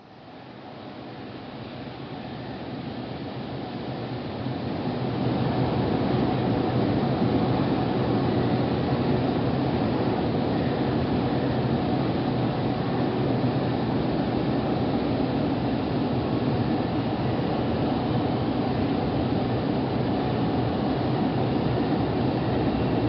Inside the South end of Motherwell DD 25.237 and 27.025 stand idling in
No. 3 Road. 40.136 sandwiched between dead locos 47.469 and 20.116
moves into No. 2 Road from North end of Depot and stops inside the Shed.
Stereo MP3 128kb file